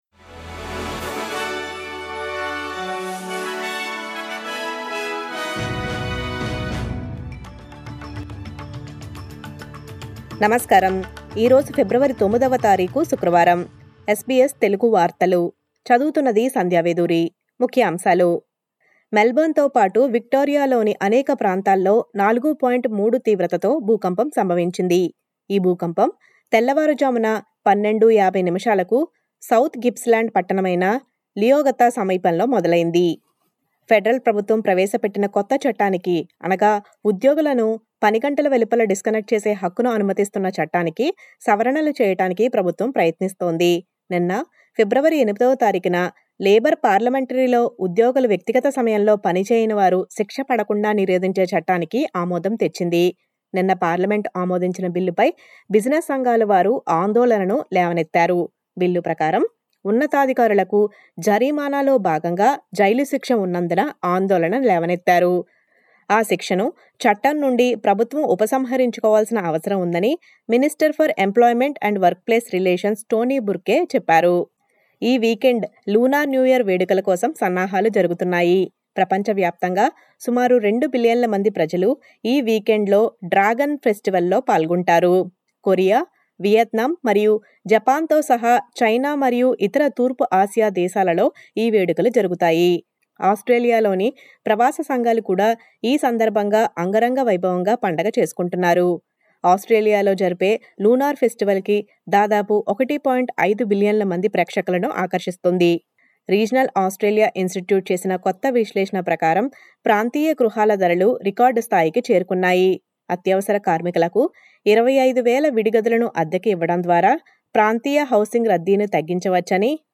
SBS తెలుగు వార్తలు.